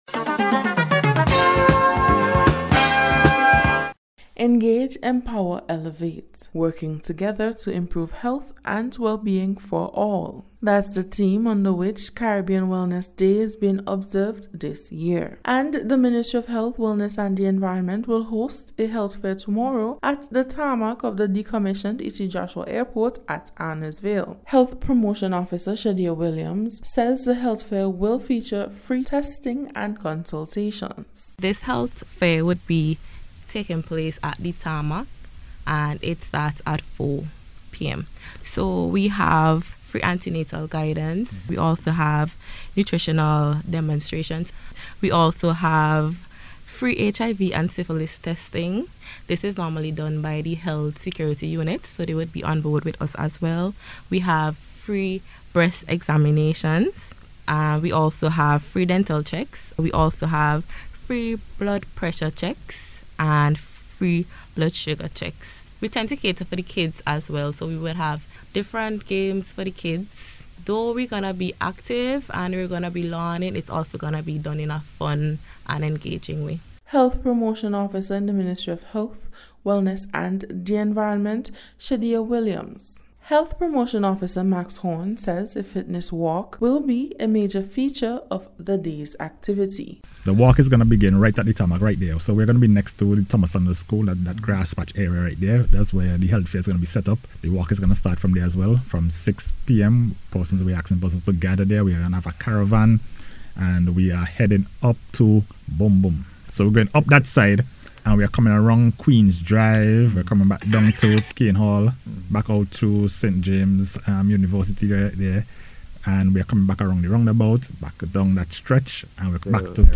WELLNESS-DAY-REPORT.mp3